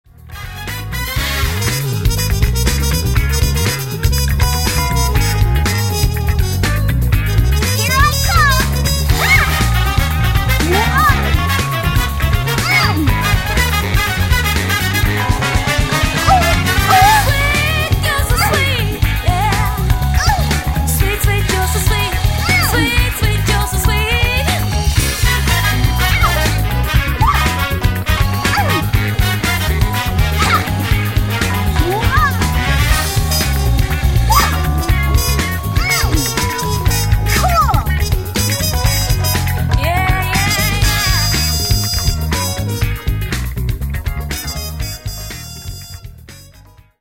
bzzz.mp3